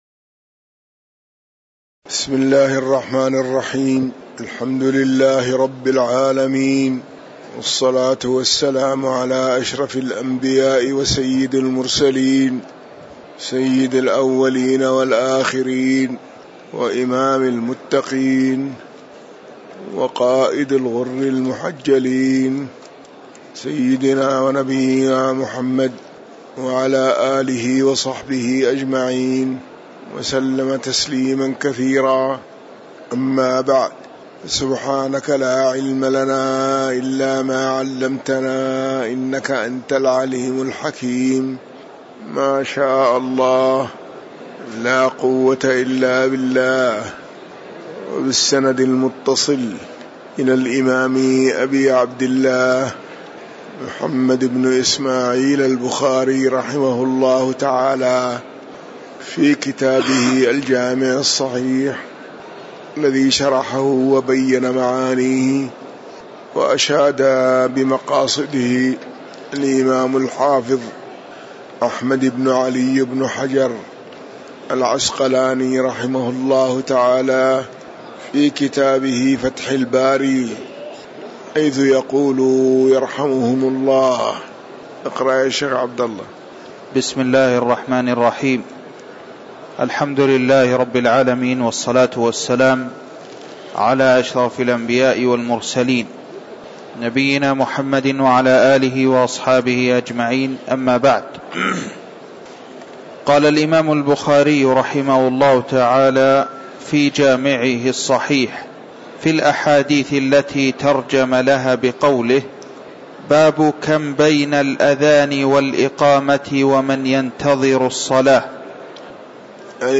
تاريخ النشر ٢١ ربيع الثاني ١٤٤١ هـ المكان: المسجد النبوي الشيخ